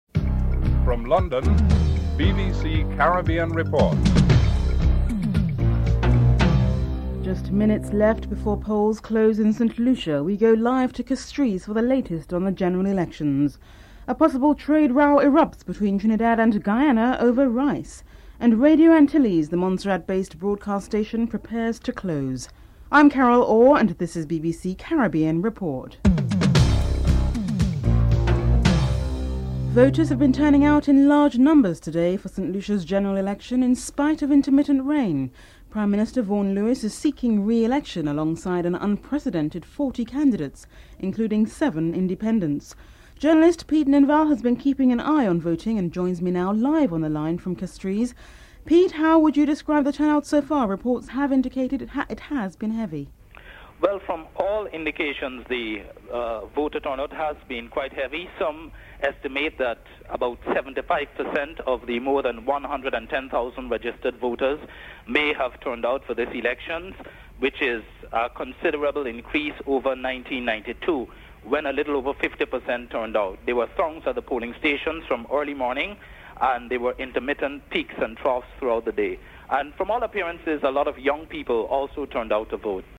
1. Headlines (00:00-00:28)